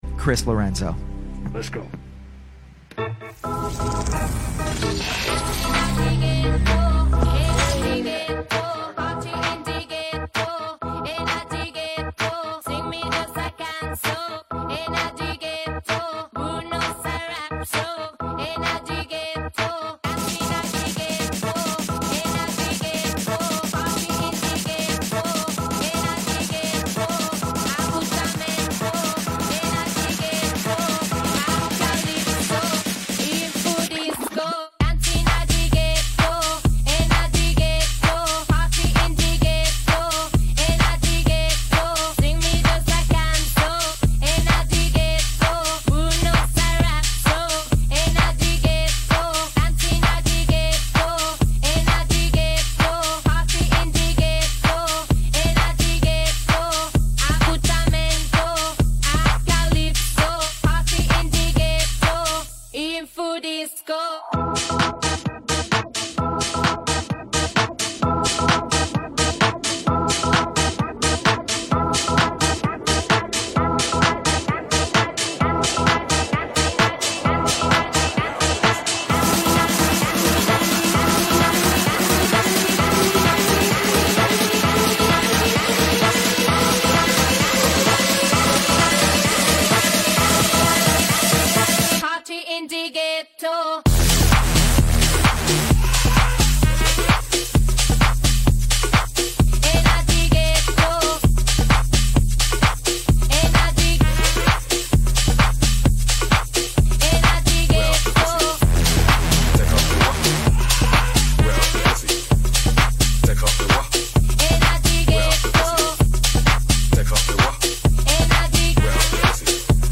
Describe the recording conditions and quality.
Quarantine Livestreams Genre: House